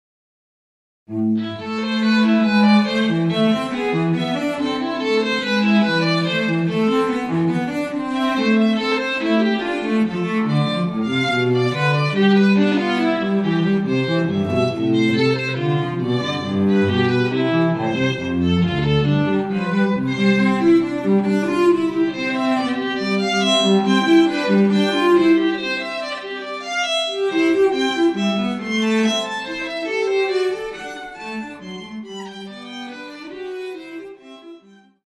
Kamermuziek
Cello
(alt) Viool